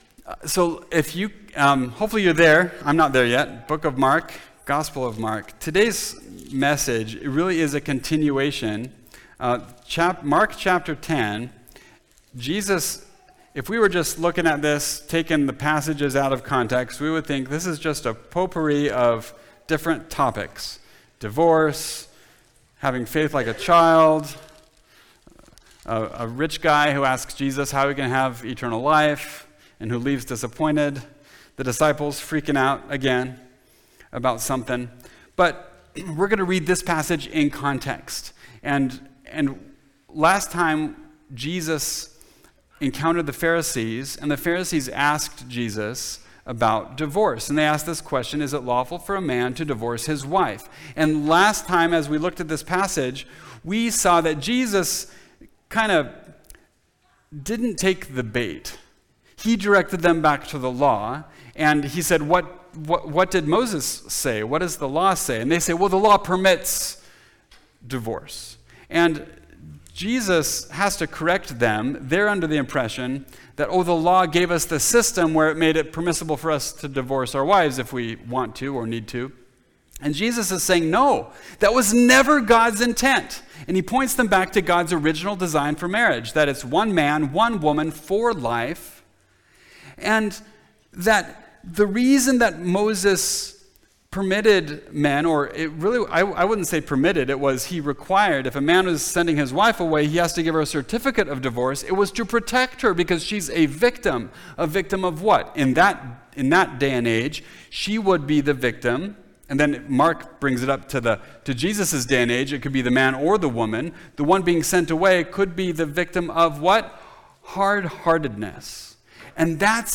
I Can’t, But He Can (Mark 10:13-31) – Mountain View Baptist Church